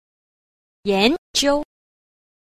6. 研究 – yánjiū – nghiên cứu